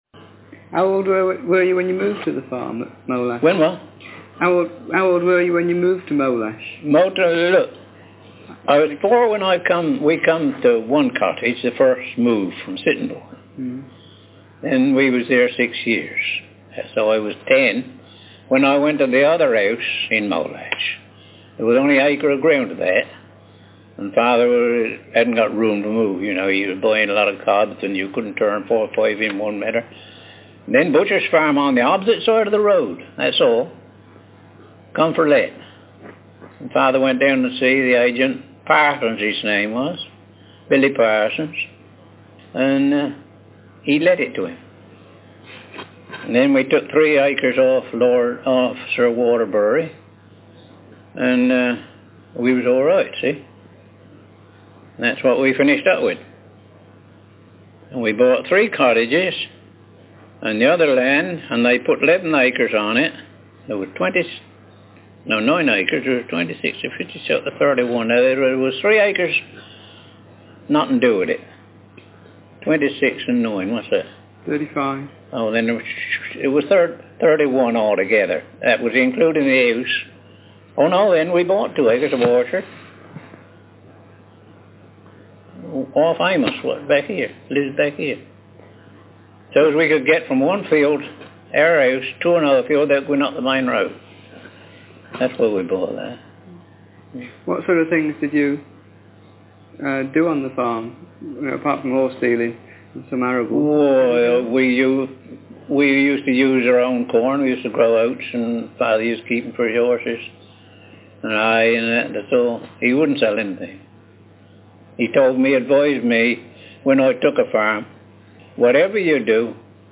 DoReCo - Language English (Southern England)
Speaker sex m Text genre personal narrative